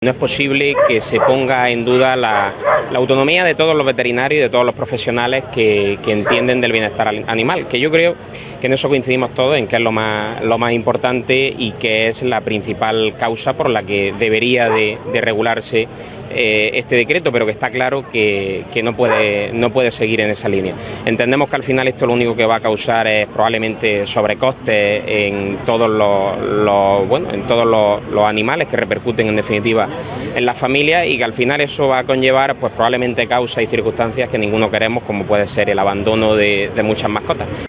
ALVARO-IZQUIERDO-DIPUTADO-ECONOMIA-MARCHA-REIVINDICATIVA-VETERINARIOS.wav